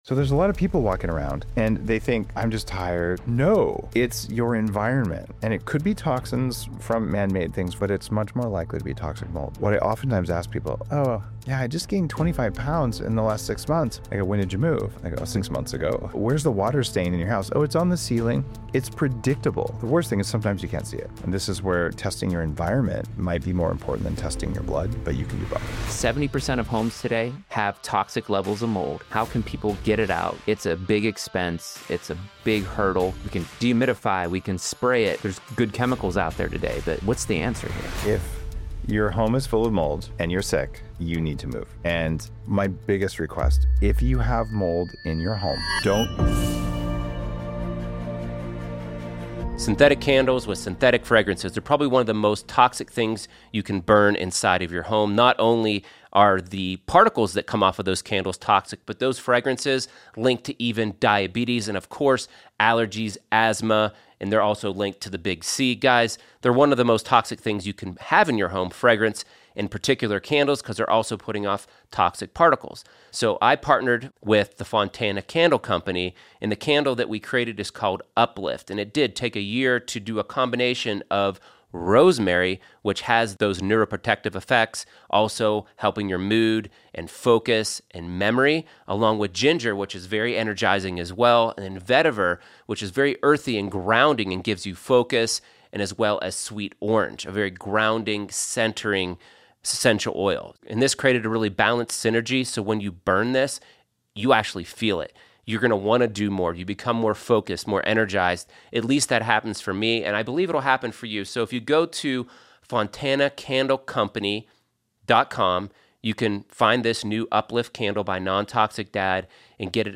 In this episode, I’m joined by biohacker and wellness expert Dave Asprey, the founder of Bulletproof and a pioneer in the world of biohacking. We dive into his journey to health, overcoming toxic mold exposure, and how it led him to develop practical biohacking strategies for better living.